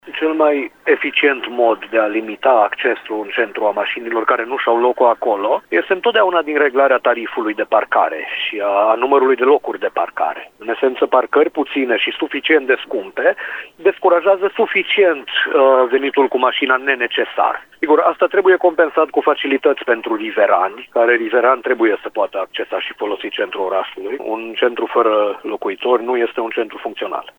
expert în mobilitate durabilă